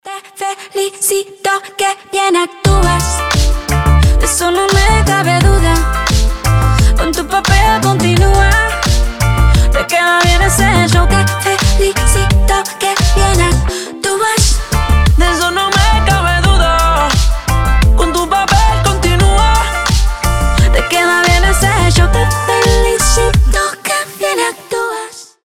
• Качество: 320, Stereo
заводные
дуэт
реггетон